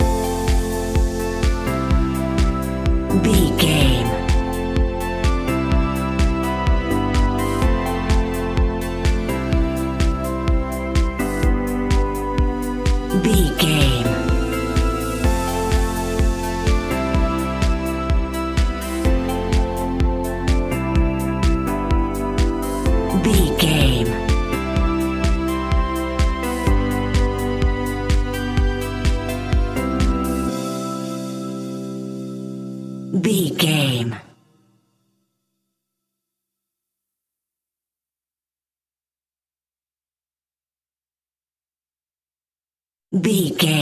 Joyful Tropical House 30 Sec.
Ionian/Major
groovy
uplifting
energetic
cheerful/happy
drums
synthesiser
drum machine
electric piano
house
electro house
synth leads
synth bass